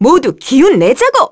Juno-Vox_Skill2_kr.wav